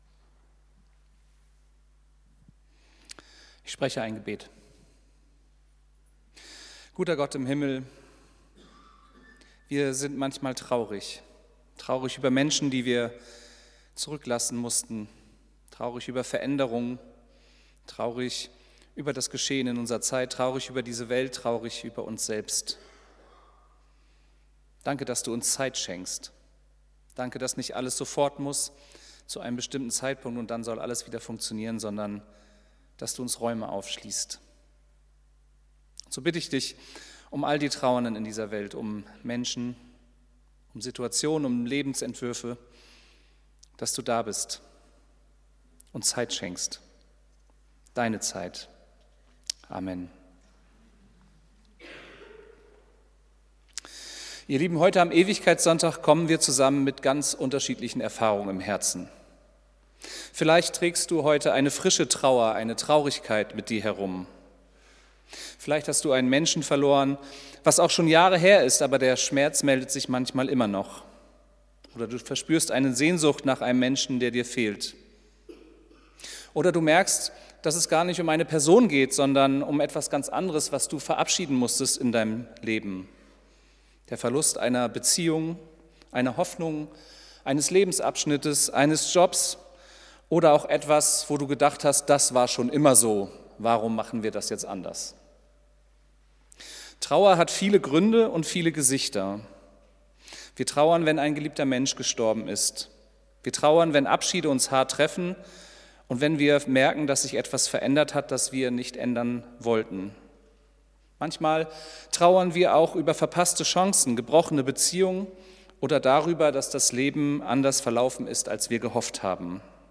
Predigt vom 23.11.2025